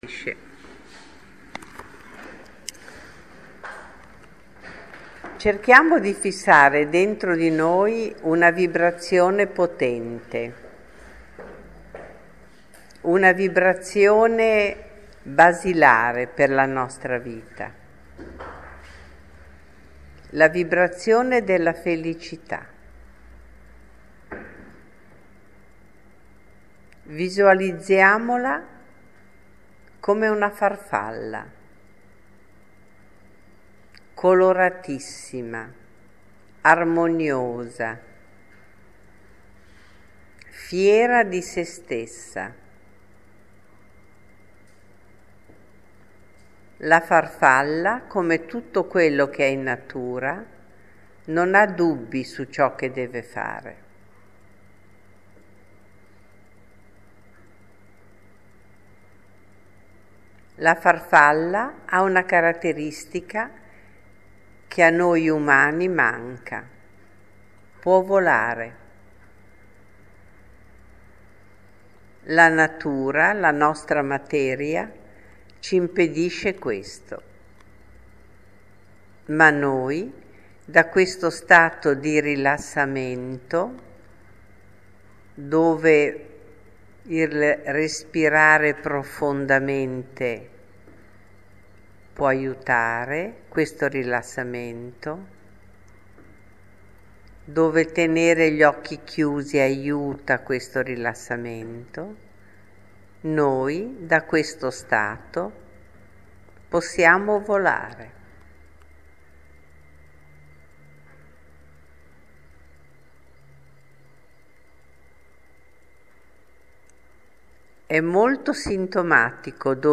Radichiamo la Felicità – meditazione